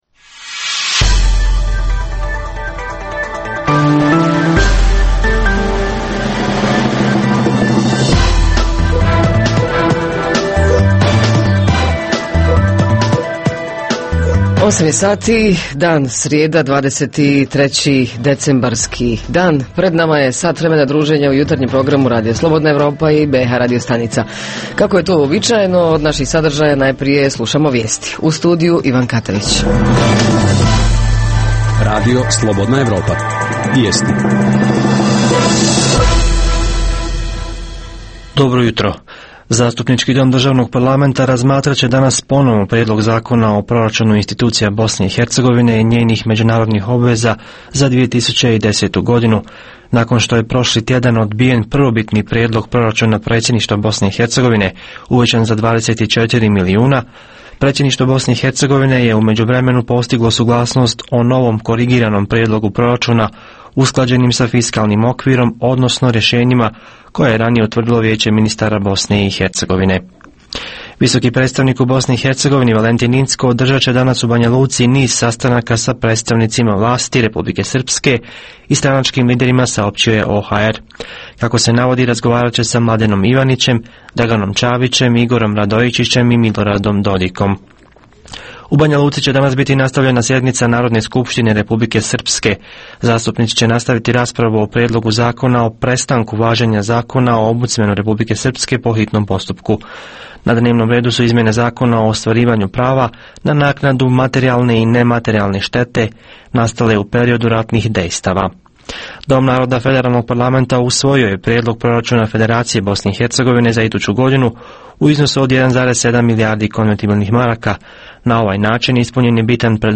Niske temperature - niz neprijatnosti ne samo u saobraćaju nego u cjelokupnoj infrastrukturi Reporteri iz cijele BiH javljaju o najaktuelnijim događajima u njihovim sredinama. Redovna rubrika Radija 27 srijedom je “Vaša prava".